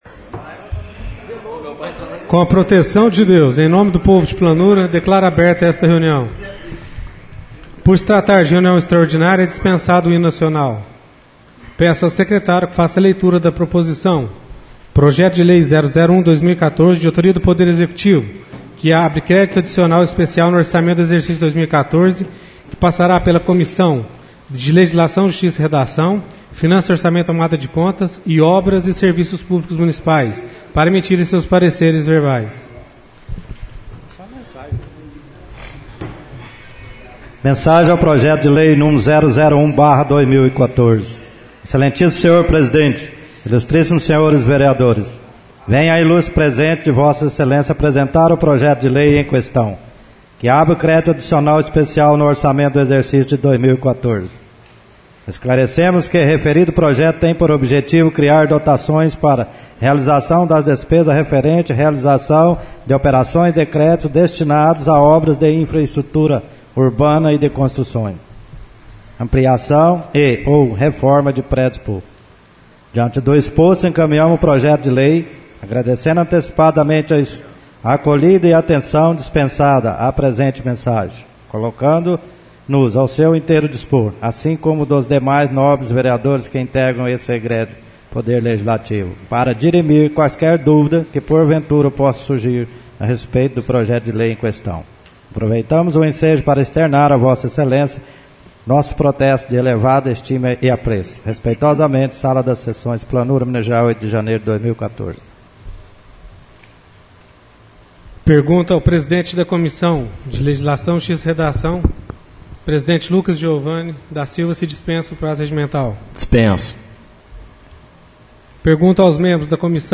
Sessão Extraordinária - 09/01/14